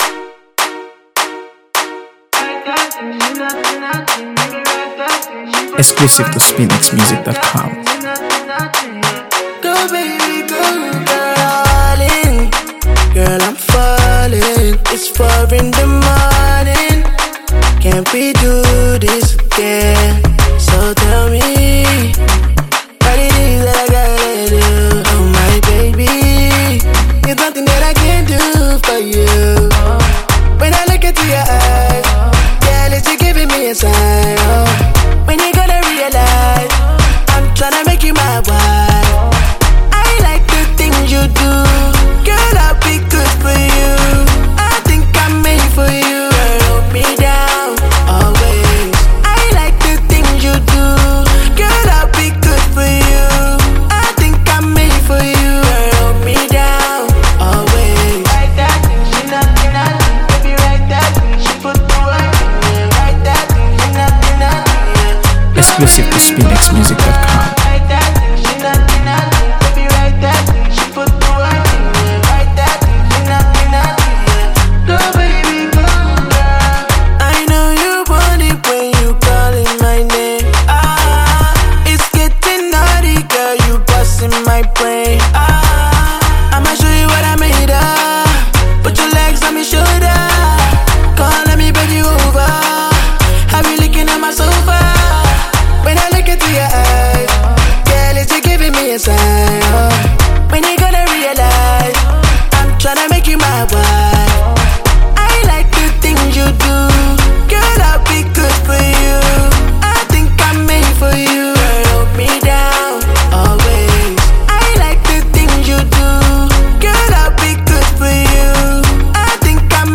AfroBeats | AfroBeats songs
The track is smooth and magnetic
laid-back yet captivating vocals
with fans praising its feel-good rhythm and catchy lyrics.